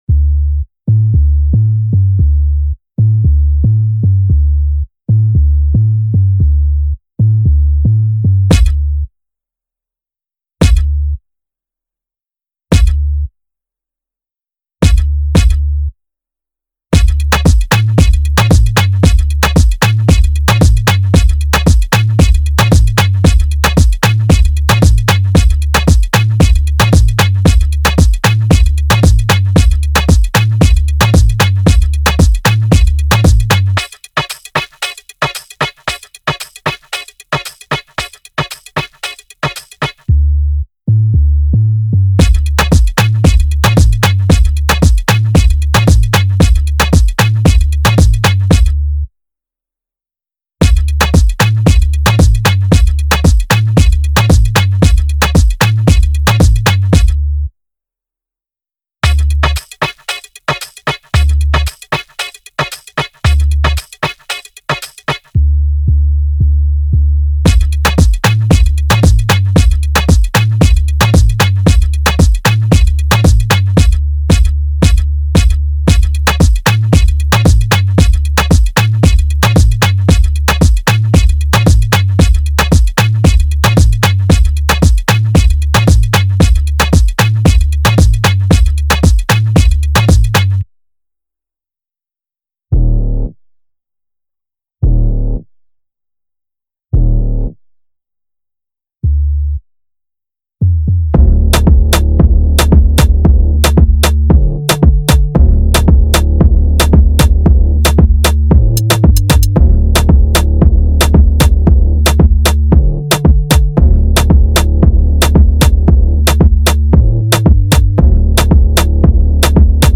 official instrumental
2025 in Latin Instrumentals